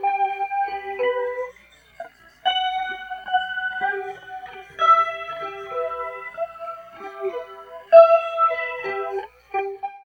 43 GUIT 1 -L.wav